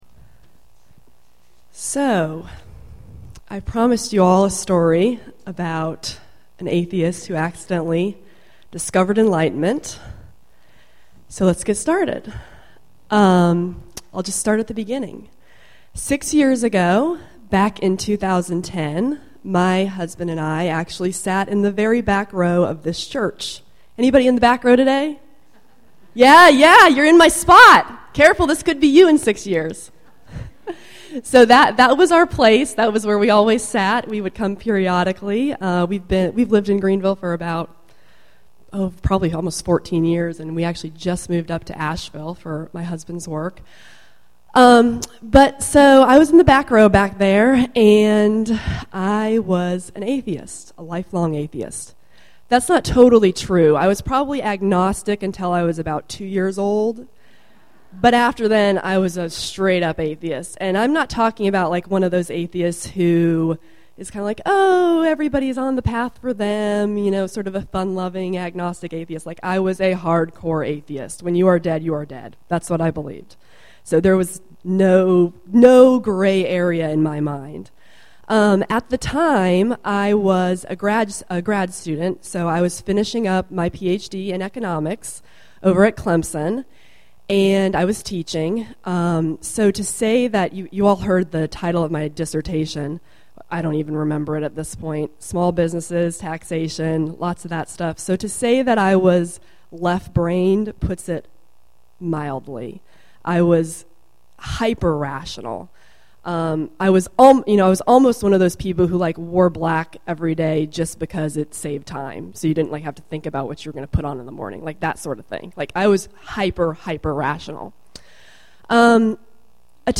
She and her husband have both experience challenging dietary changes and new approaches to health and wellness. Not that they ever are, but this will not be a typical Sunday at the GUUF.